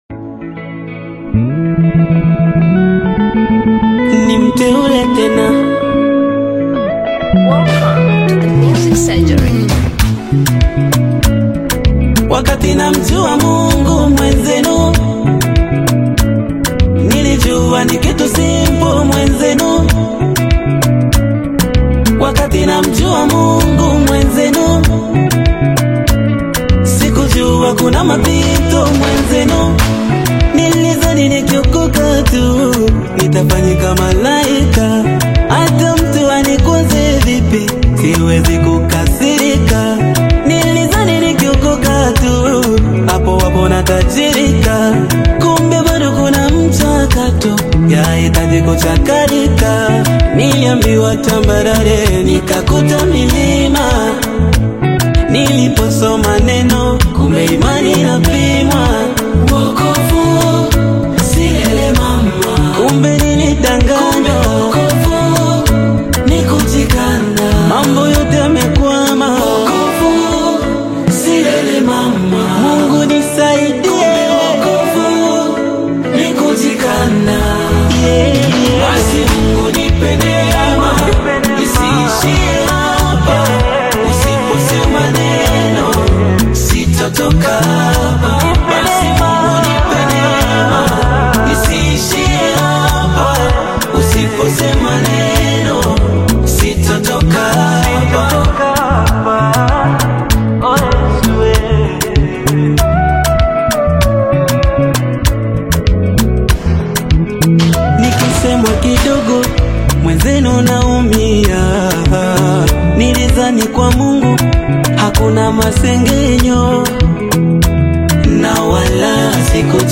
inspiring Tanzanian gospel single
Driven by powerful Swahili worship lyrics about salvation
soulful vocal delivery
uplifting contemporary gospel sound